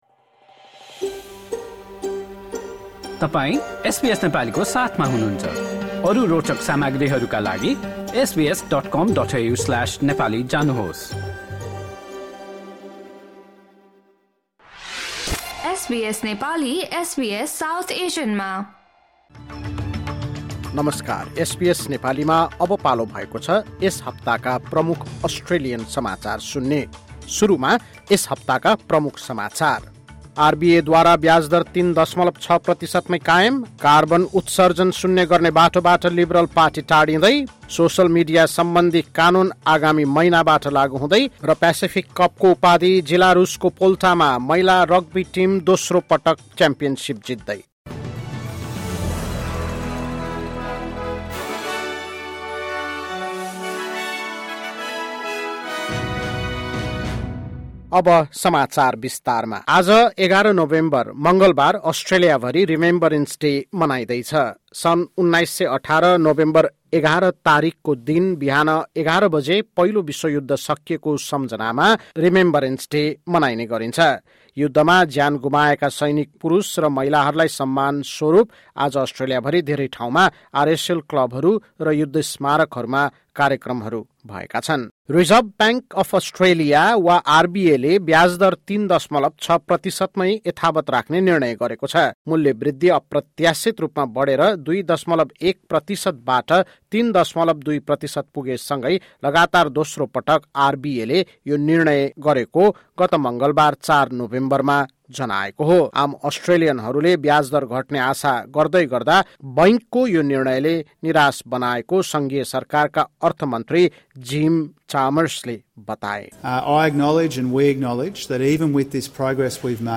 पहिलो विश्व युद्ध सकिएको सम्झनामा ‘रिमेम्ब्रेन्स डे’ सम्पन्न, कार्बन उत्सर्जन शून्य गर्ने बाटोबाट लिबरल पार्टी टाढिँदै र जीलारूसले पायो प्यासिफिक कपको उपाधि लगायत एक हप्ता यताका प्रमुख घटनाहरू बारे एसबीएस नेपालीबाट समाचार सुन्नुहोस्।